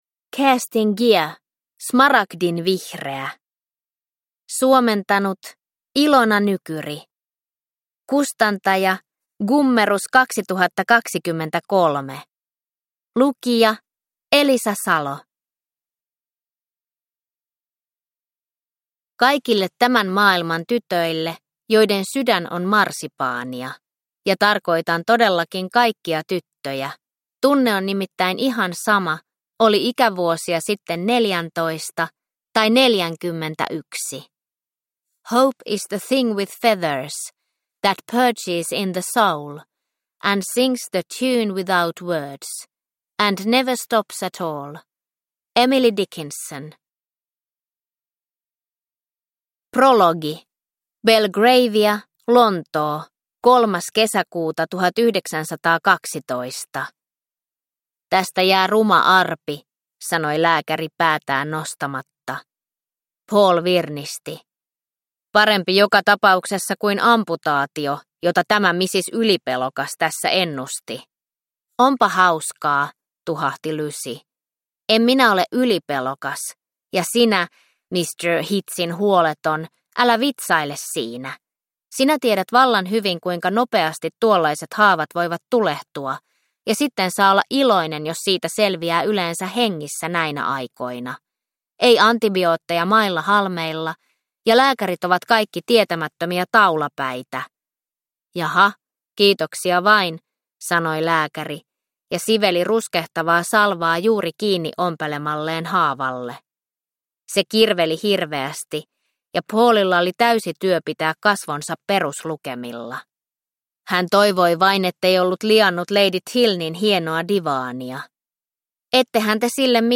Smaragdinvihreä – Ljudbok